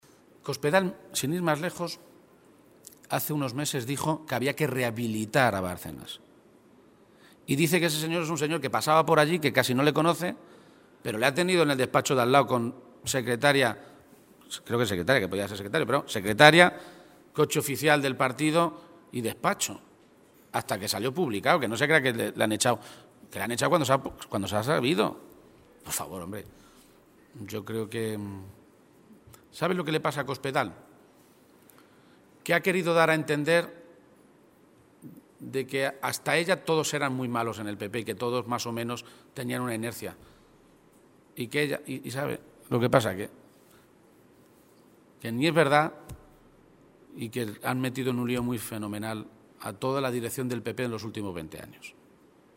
Page se pronunciaba de esta manera a preguntas de los medios de comunicación, hoy, en Toledo, en una rueda de prensa en la que, en relación con el debate celebrado ayer en el Congreso, destacaba “la casualidad de que el único pacto que ofreció ayer Rajoy fuera un pacto sobre corrupción, precisamente ahora, cuando su partid está metido en el lío de los sobres y Bárcenas”.
Cortes de audio de la rueda de prensa